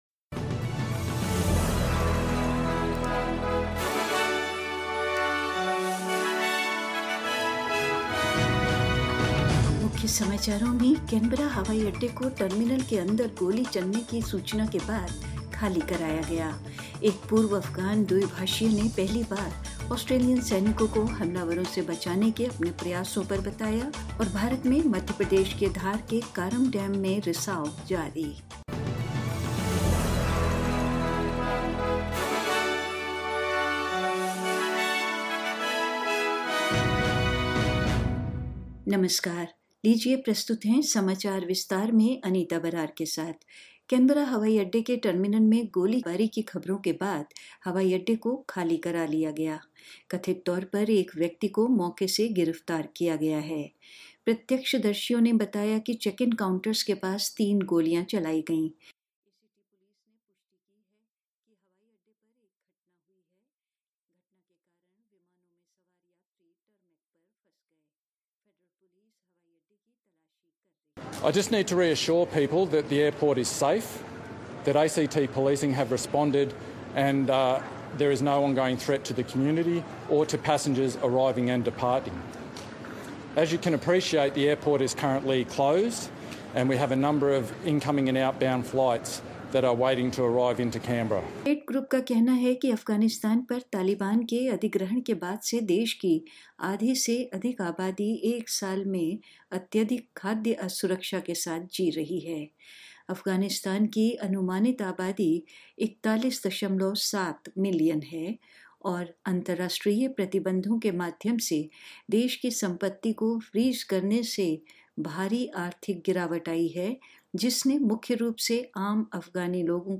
In this latest bulletin: Canberra Airport evacuated following reports of gunshots inside the terminal; A former Afghan interpreter speaks out for the first time on his efforts to save Australian soldiers from a rogue attacker; Water continues to seep through the Karam dam in Dhar of India's Madhya Pradesh and more news.